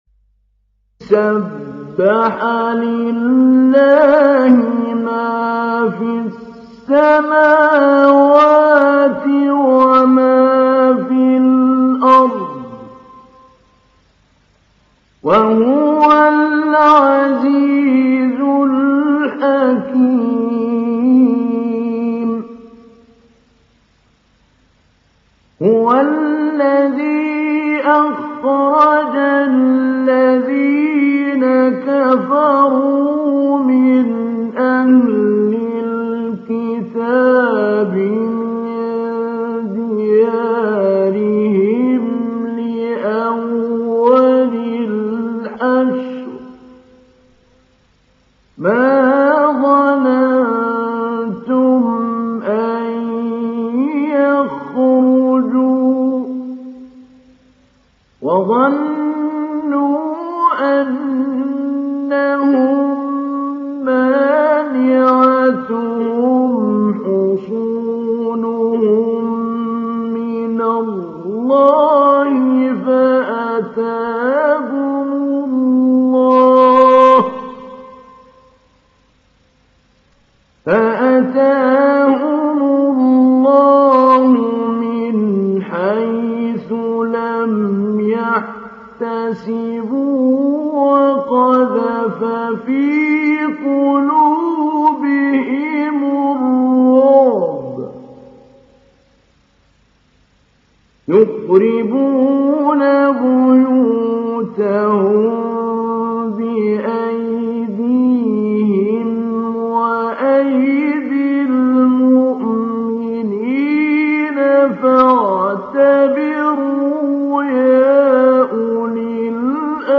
تحميل سورة الحشر mp3 محمود علي البنا مجود (رواية حفص)
تحميل سورة الحشر محمود علي البنا مجود